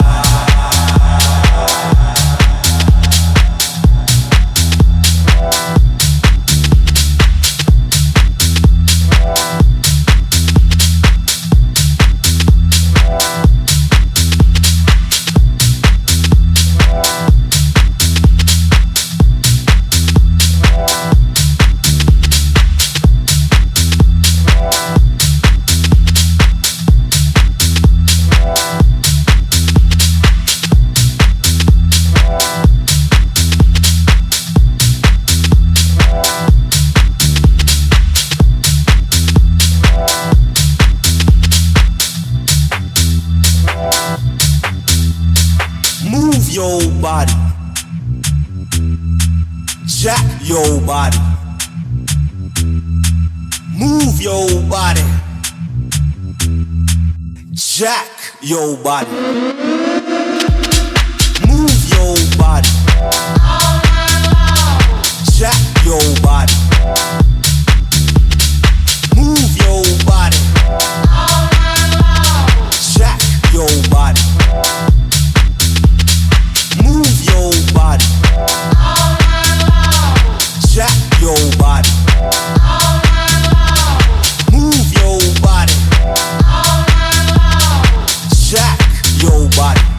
分厚いベースラインのグルーヴとシンセパッドがフロアをじっくりとロックしスポークン・ワードで煽る